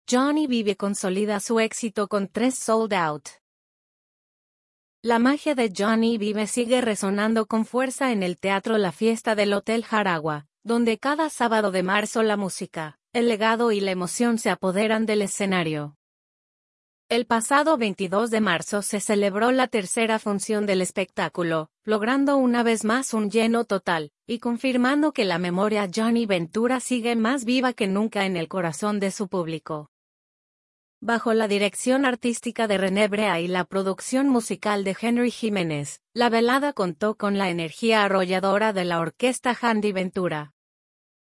La magia de Johnny Vive sigue resonando con fuerza en el Teatro La Fiesta del hotel Jaragua, donde cada sábado de marzo la música, el legado y la emoción se apoderan del escenario.
la velada contó con la energía arrolladora de la orquesta Jandy Ventura.